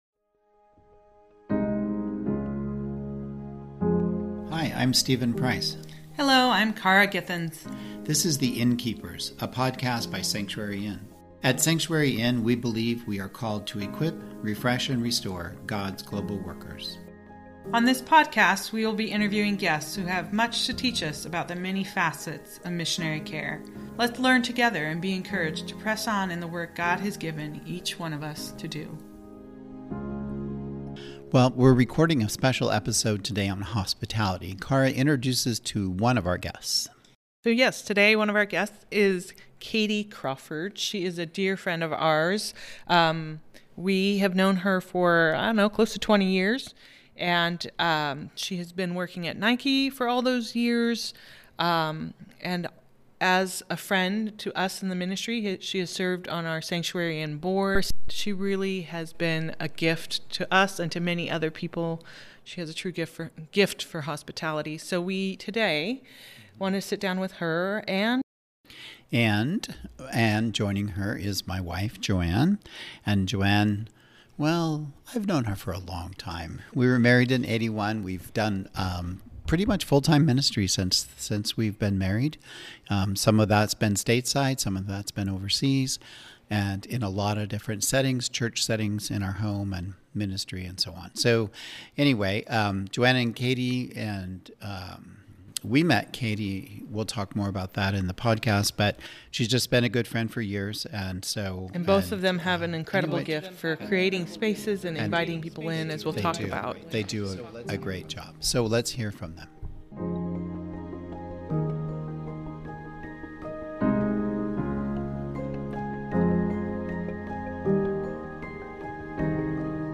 On this podcast we will be interviewing guests who have much to teach us about the many facets of Missionary Care.